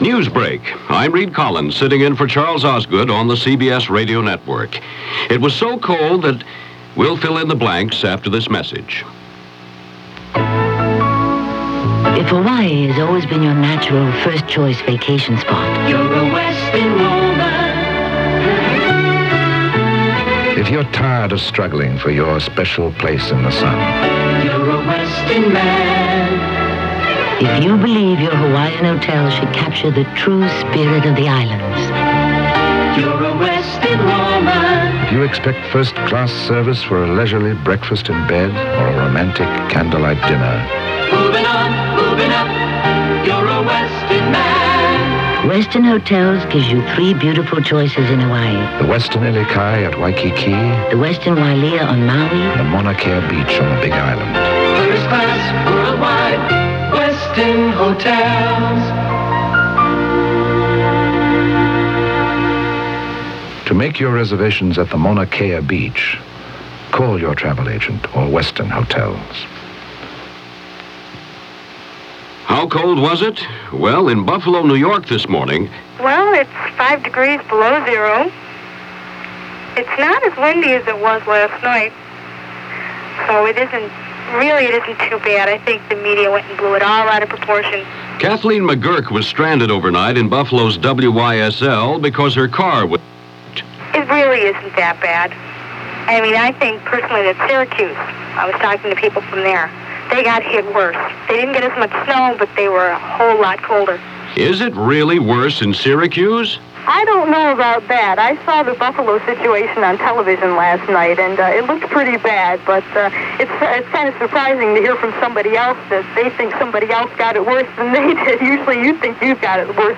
January 12, 1982 – CBS Radio News + Newsbreak – Gordon Skene Sound Collection –